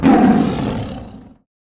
CosmicRageSounds / ogg / general / combat / creatures / tiger / she / turn1.ogg